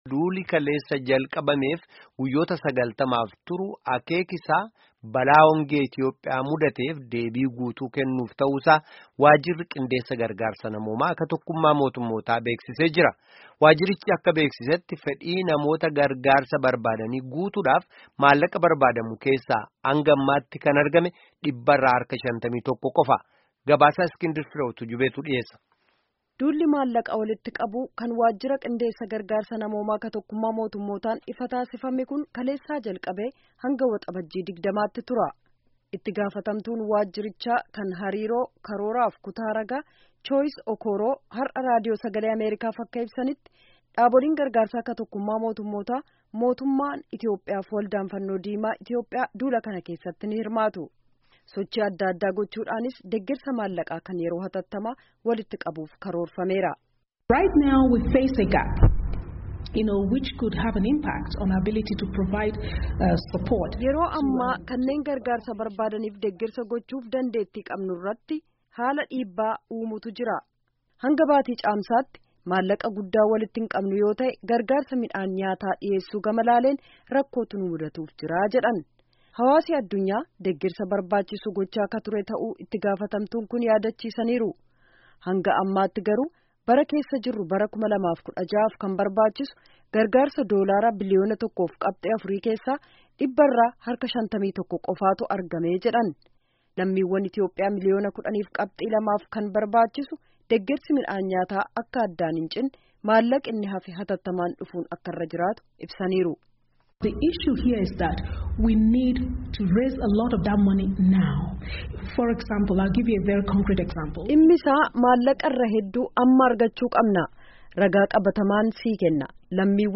Gabaasa sagalee.